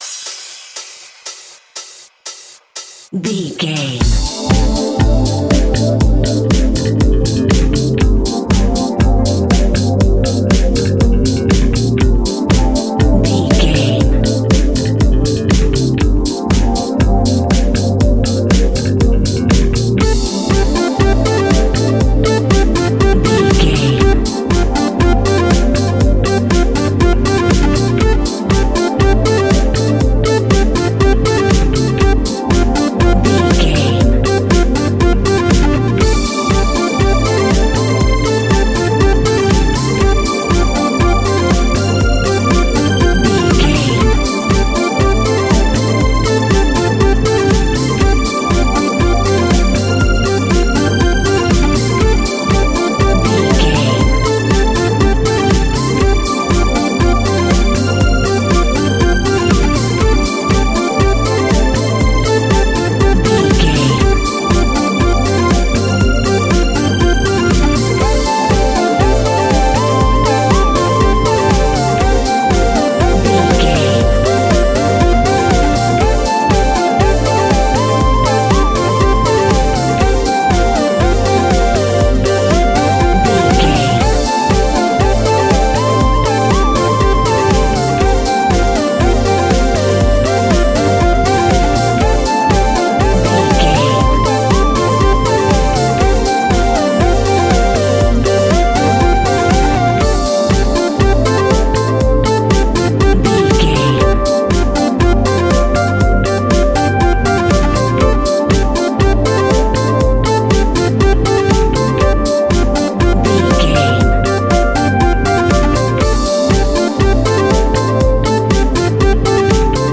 Ionian/Major
D
groovy
uplifting
futuristic
driving
energetic
repetitive
drum machine
synth leads
electronic music
techno music
synth bass
synth pad